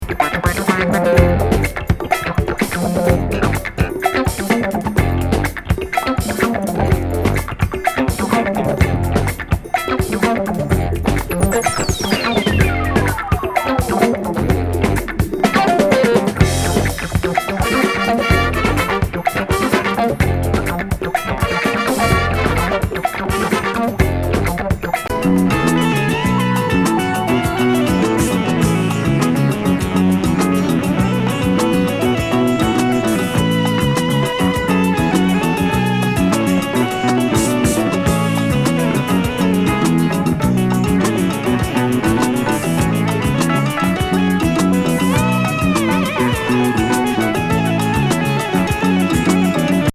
ラテン・ディスコ・ロック「UNKNOWN